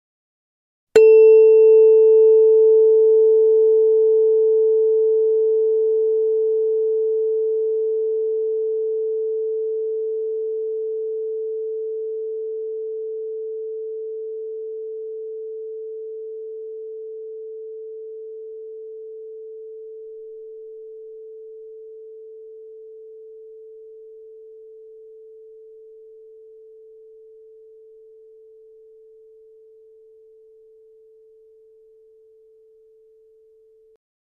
Звуки камертона
Нота ми второй октавы